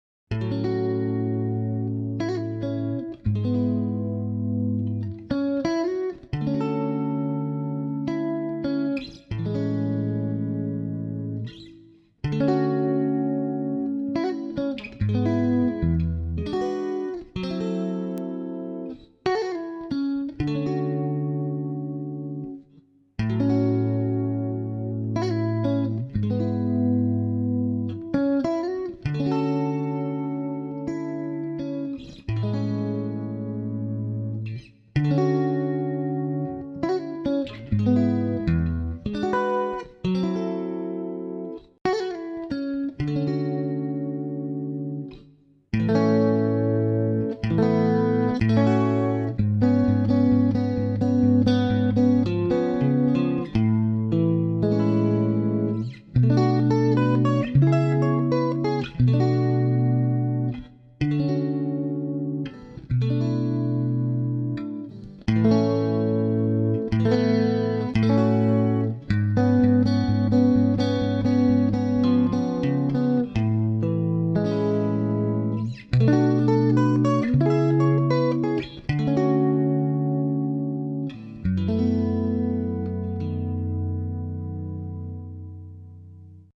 Juste une seule guitare.